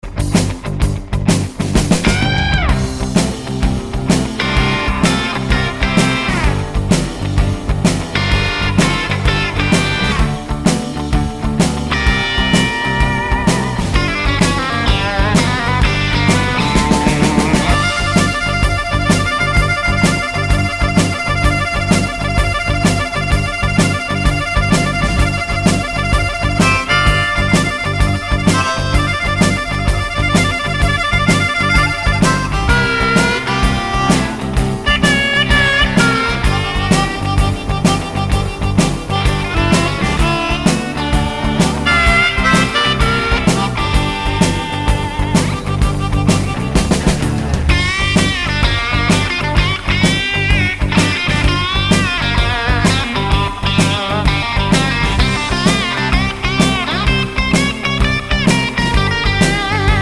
no Backing Vocals Rock 3:10 Buy £1.50